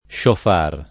sciofar → shōfār